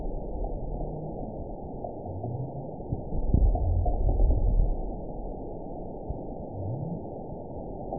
event 916117 date 12/25/22 time 09:16:48 GMT (2 years, 11 months ago) score 8.61 location INACTIVE detected by nrw target species NRW annotations +NRW Spectrogram: Frequency (kHz) vs. Time (s) audio not available .wav